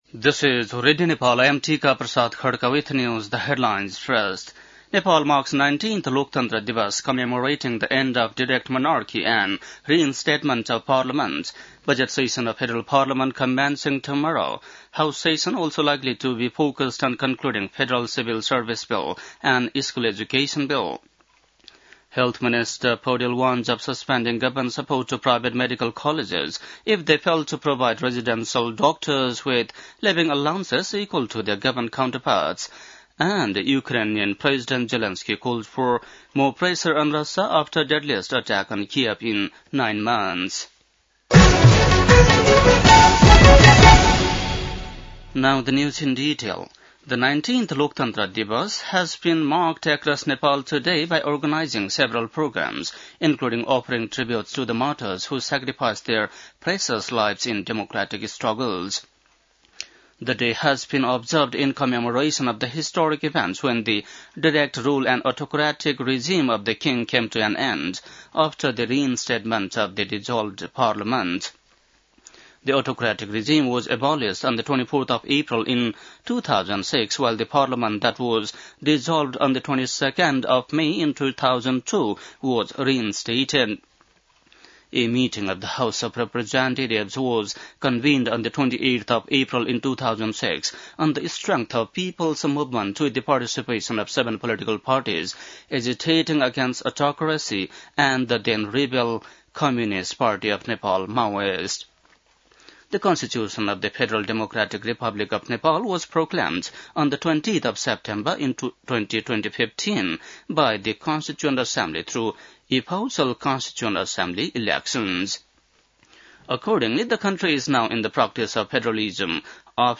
An online outlet of Nepal's national radio broadcaster
बेलुकी ८ बजेको अङ्ग्रेजी समाचार : ११ वैशाख , २०८२